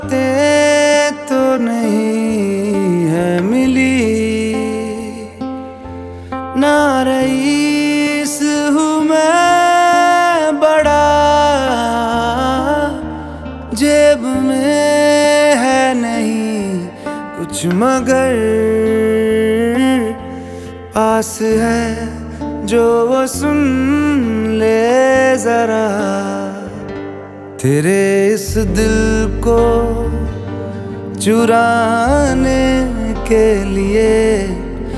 Жанр: Соундтрэки
Bollywood, Indian, Soundtrack